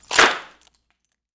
pcp_clap10.wav